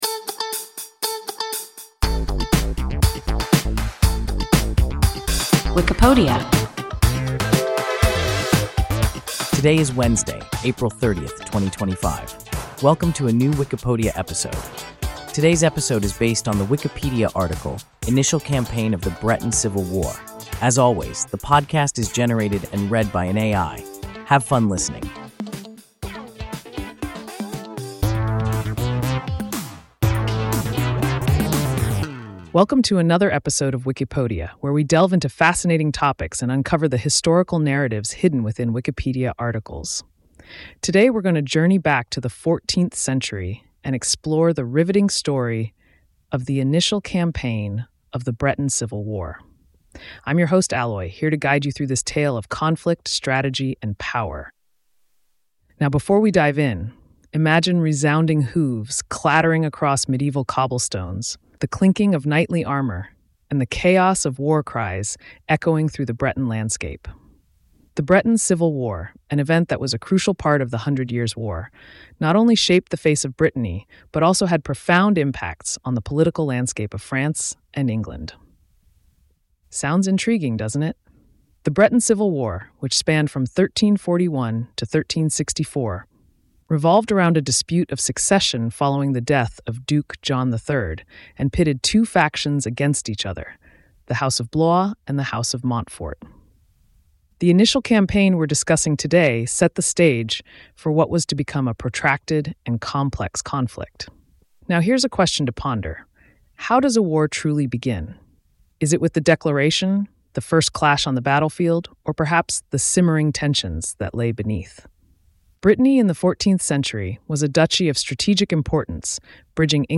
Initial campaign of the Breton Civil War – WIKIPODIA – ein KI Podcast